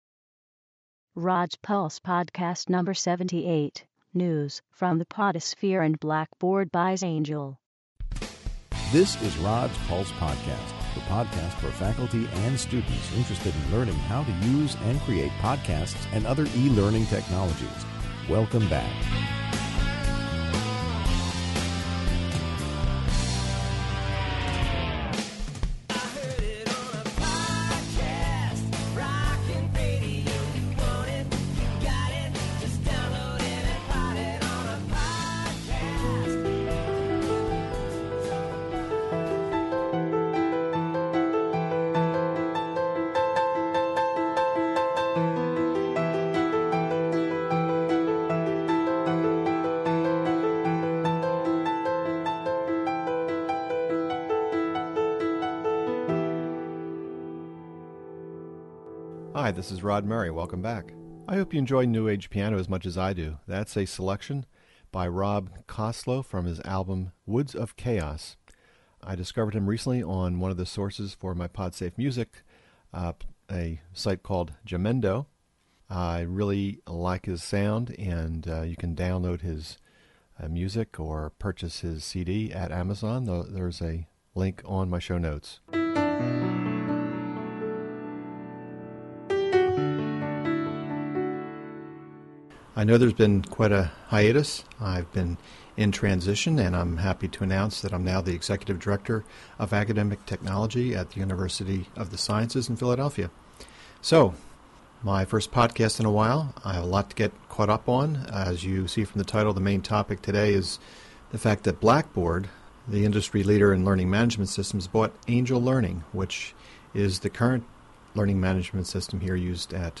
Report from the 2009 Angel Users Conference in Chicago
New Age Piano